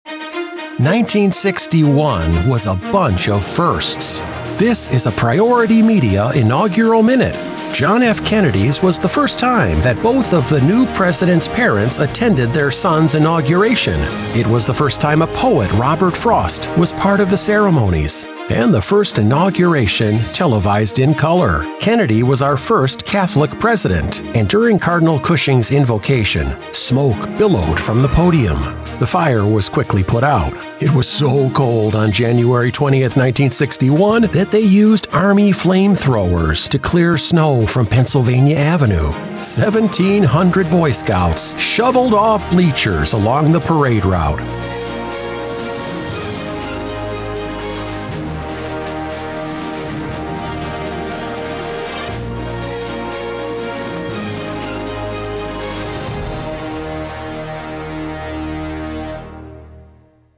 My client stations are pitching "Inaugural History Minute" features for air January 5-19, the two weeks leading-up to an event the world will watch, and hear. Here's a sample:
• Each times-out to 45 seconds -- allowing for your local sponsorship copy -- to fit into a 60-second spot avail, in any format (so you can bundle multiple stations).